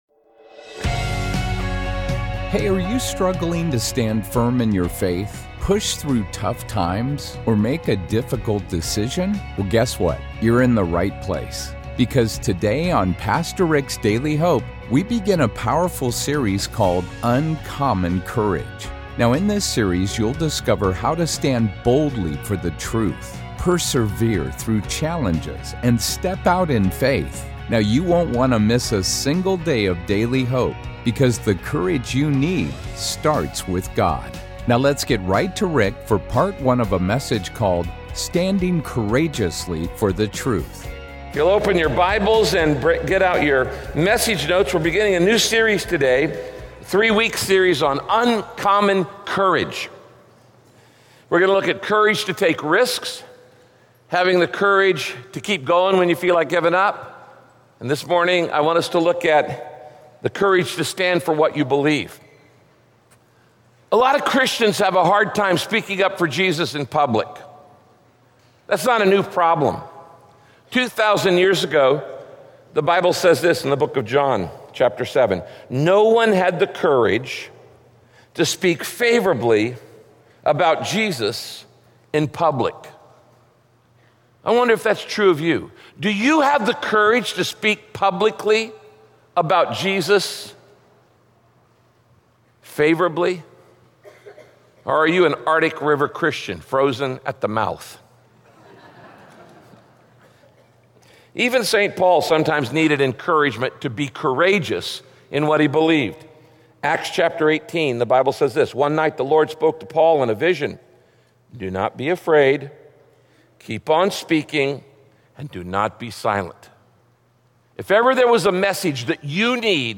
Many worldviews go against the truth of God's Word. In this message, Pastor Rick examines different worldviews and the folly that comes from following them.